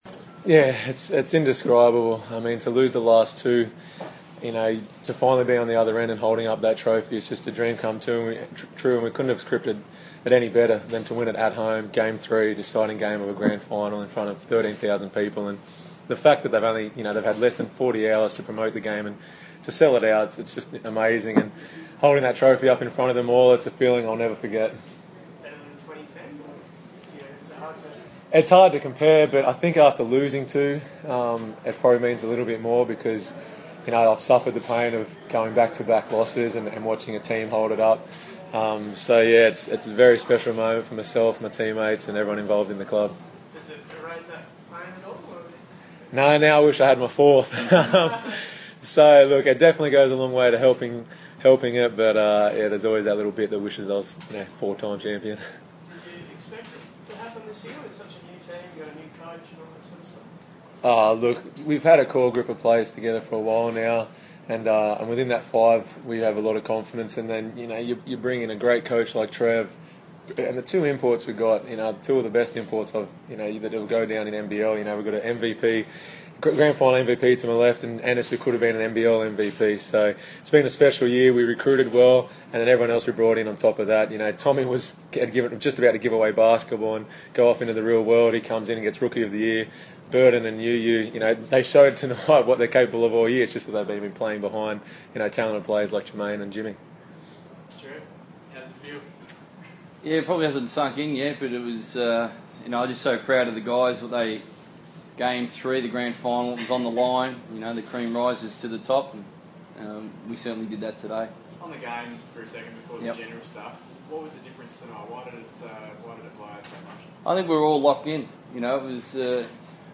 Post-Championship Press Conference